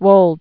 (wōldz)